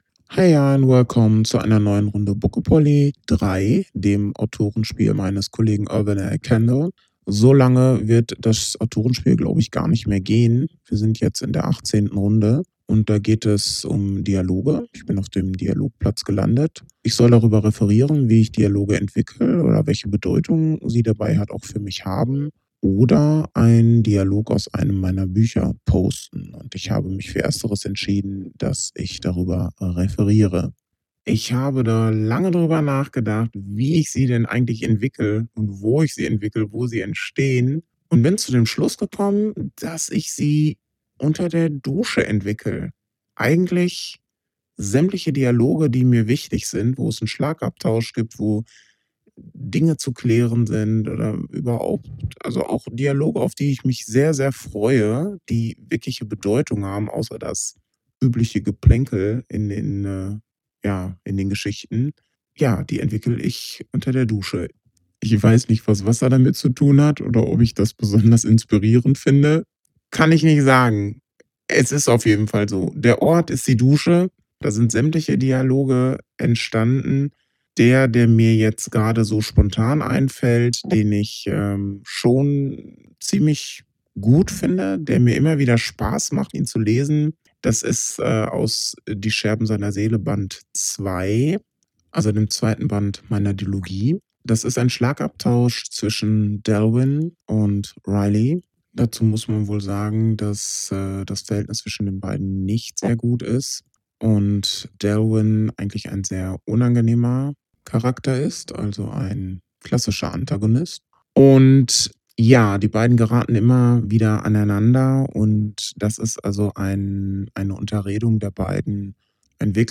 Ich habe mit meinem – neuen Mikro *gg – mal wieder eine Audio eingesprochen; da erfahrt Ihr, an welchen Dialogen mein Herzblut hängt, wie und vor allem wo ich sie mit Vorliebe entwickle (wusste ich tatsächlich auch erst, nachdem ich mir jetzt Gedanken darüber gemacht habe) und welches einer meiner liebsten Dialoge ist.